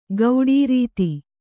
♪ gauḍī rīti